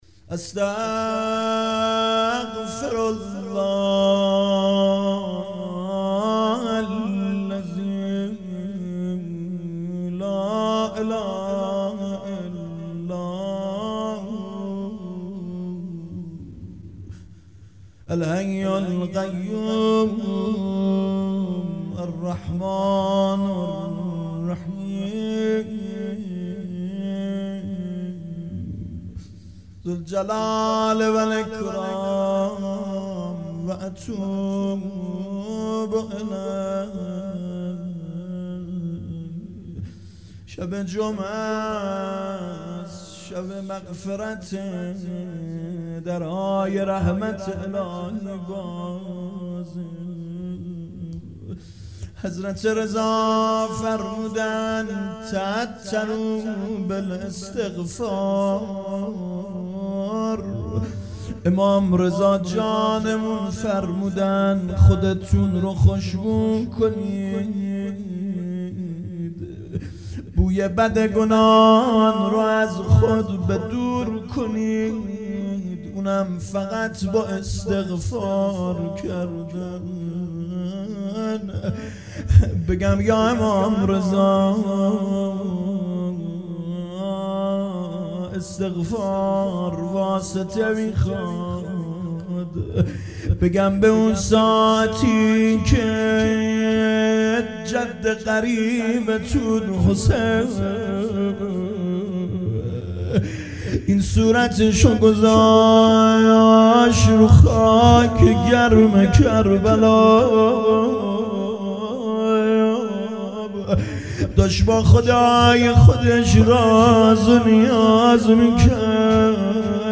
روضه
ایام شهادت حضرت زهرا(س)